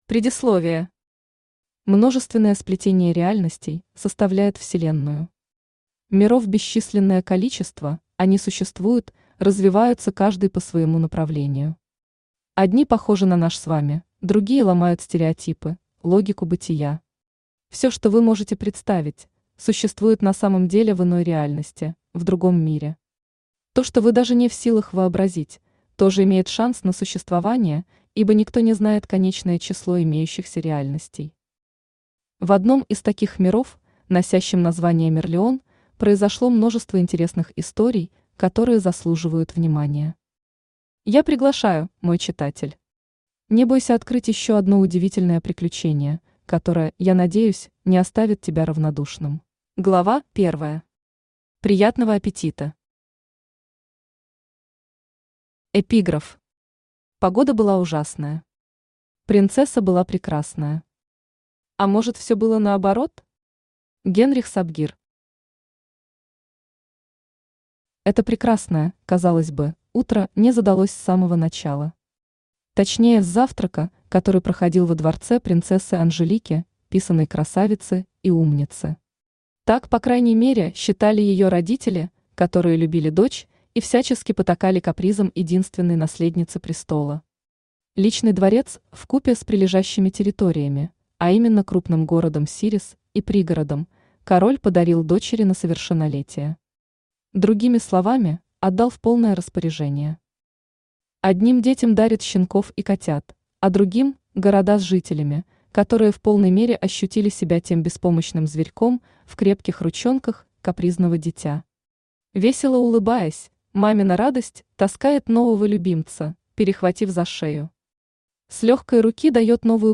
Аудиокнига Аина | Библиотека аудиокниг
Aудиокнига Аина Автор Алена Бердникова Читает аудиокнигу Авточтец ЛитРес.